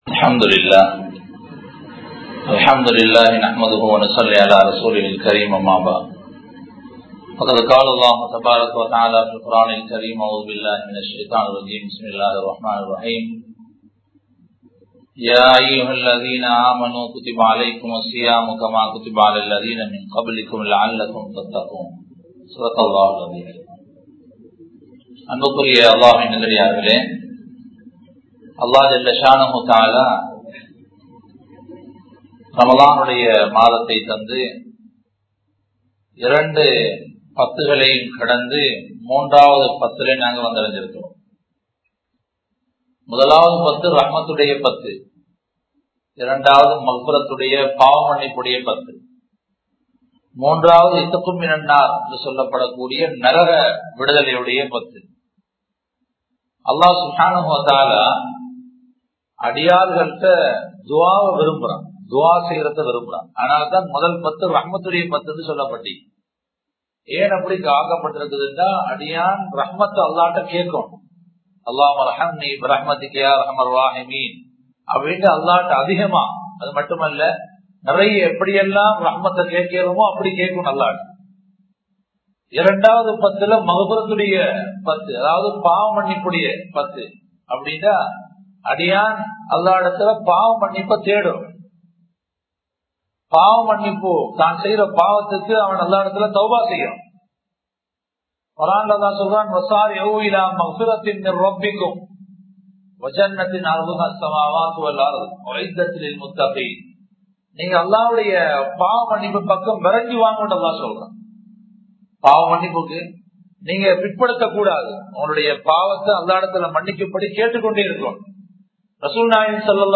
Eemanudaiya Soathanai (ஈமானுடைய சோதனை) | Audio Bayans | All Ceylon Muslim Youth Community | Addalaichenai
Muhiyadeen Jumua Masjith